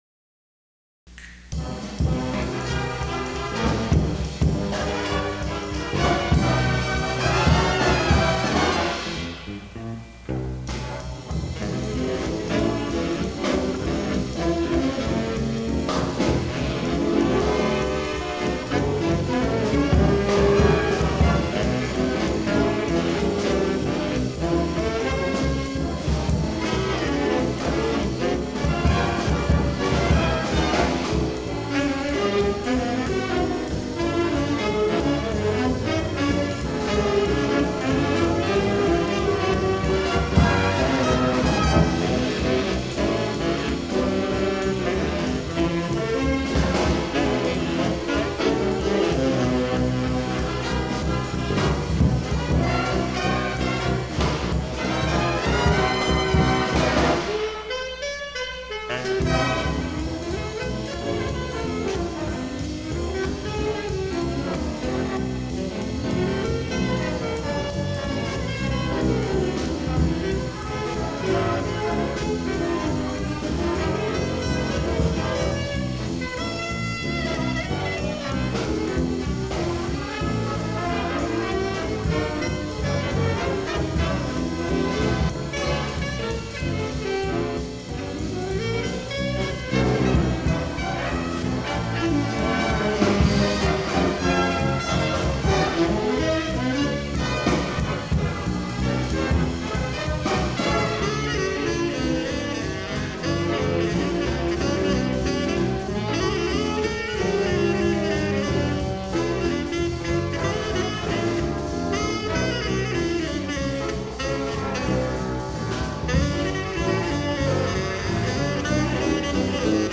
This page lists many of my original charts (compositions and arrangements) for big band.
In these cases, I generated MIDI-based recordings using Dorico and some nice sample libraries. I then used an audio editor to add solos (which I played from a keyboard) and piano comping as needed.